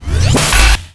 get_bullet.wav